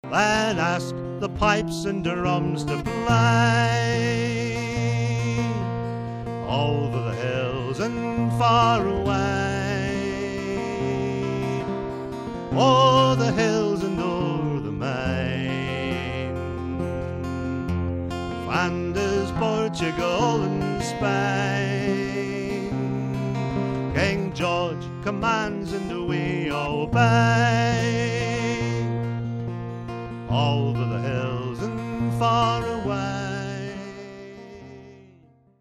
He has an excellent voice, claiming that he is not a guitarist and the guitar is merely there as background support. His intricate playing, in a variety of styles, suggests otherwise.